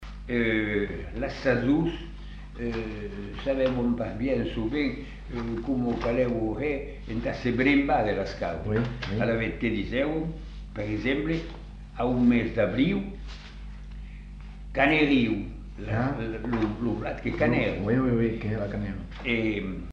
Lieu : Masseube
Effectif : 1
Type de voix : voix d'homme
Production du son : récité
Classification : proverbe-dicton